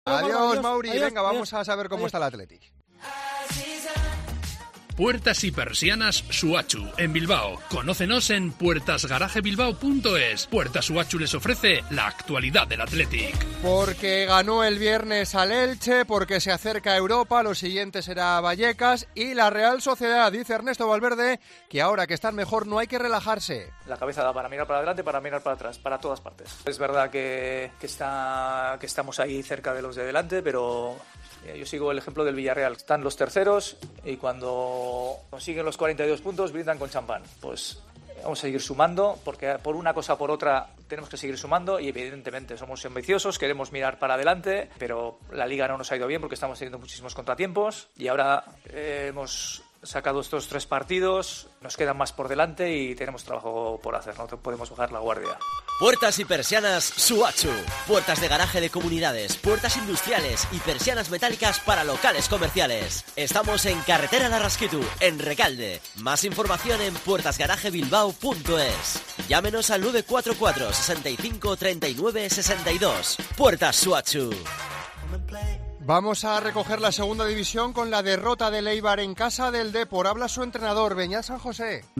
En el programa 'Deportes en Herrera en Cope en Euskadi', el técnico ha advertido que, ahora que el equipo está mejor, no es momento para relajarse.